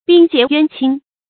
冰潔淵清 注音： ㄅㄧㄥ ㄐㄧㄝ ˊ ㄧㄨㄢ ㄑㄧㄥ 讀音讀法： 意思解釋： 猶冰清玉潔。